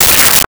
Space Gun 12
Space Gun 12.wav